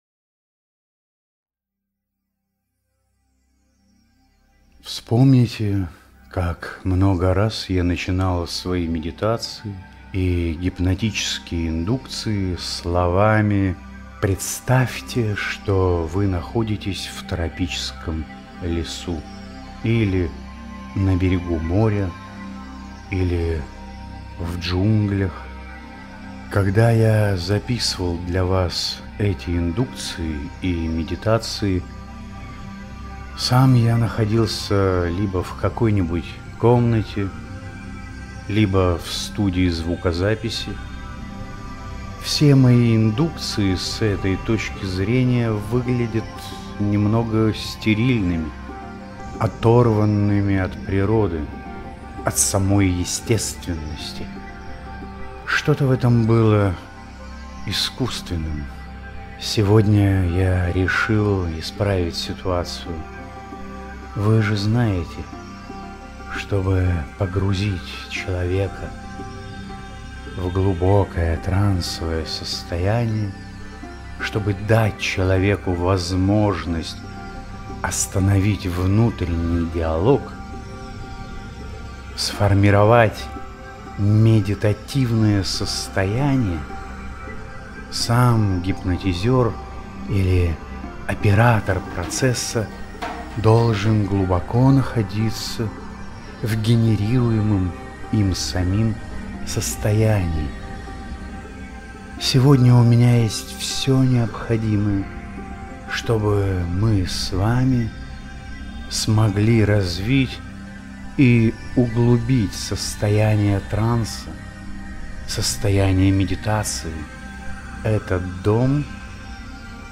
Гипноз - Медитация. Восстановление энергии. Вступление.mp3